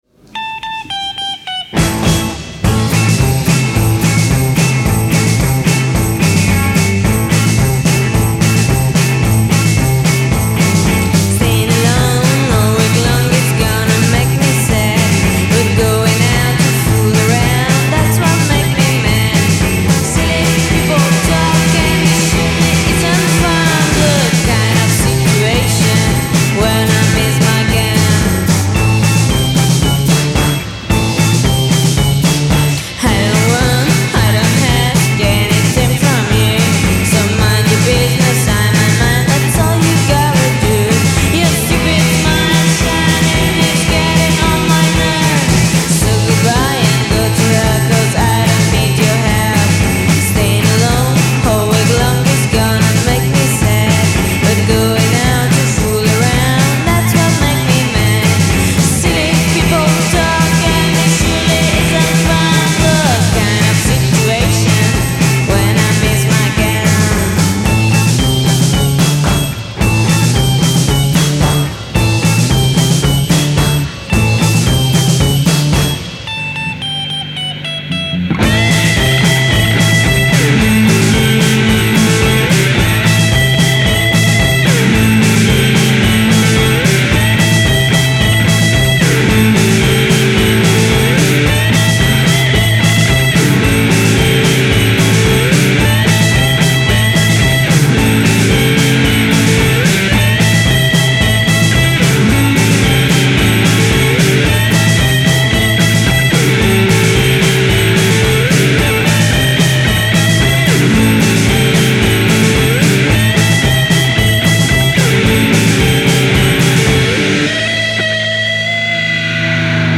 twangy voice